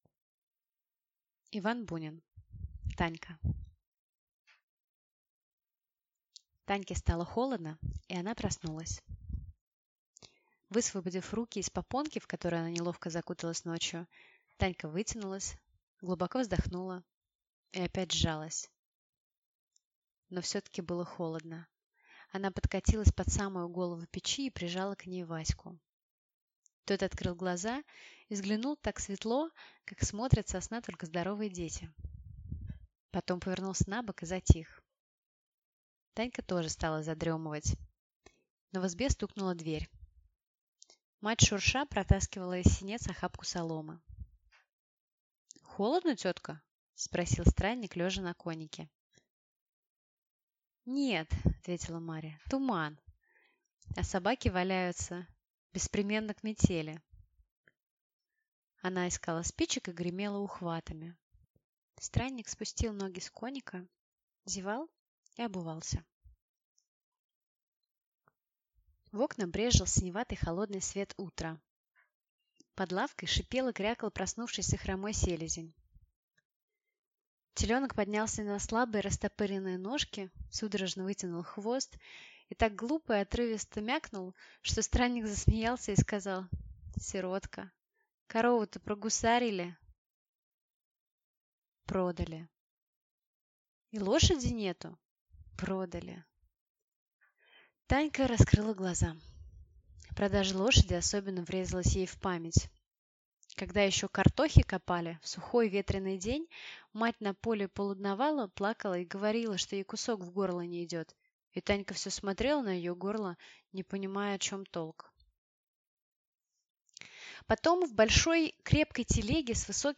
Aудиокнига Танька